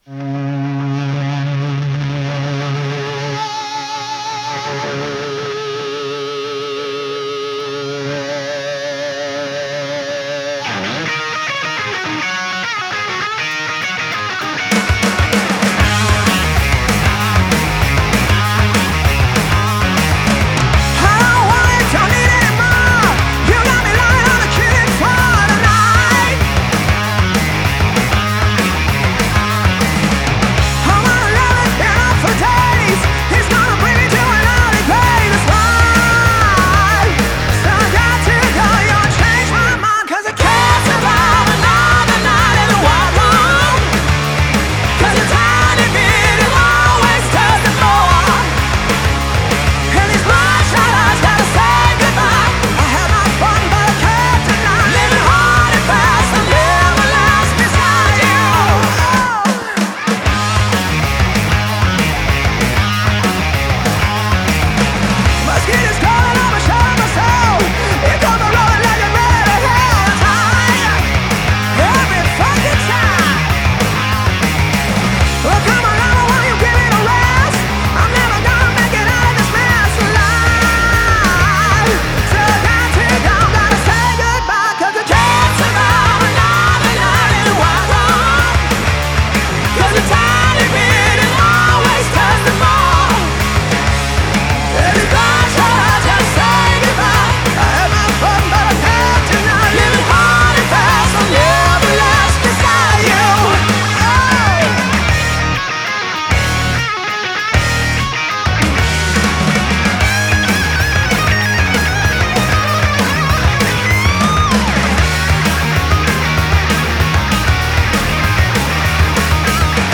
پاپ راک